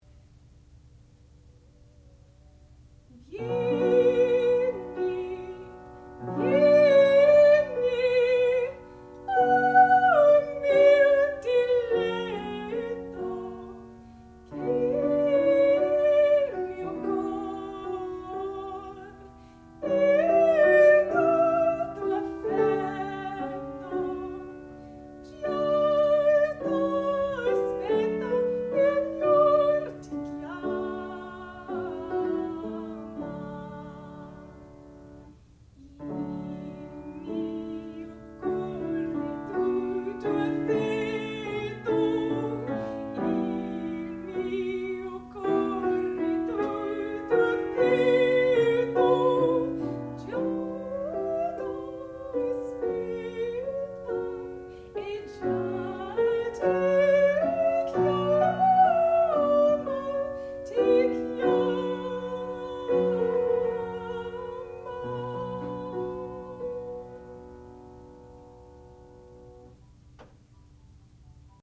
sound recording-musical
classical music
piano
Student Recital
soprano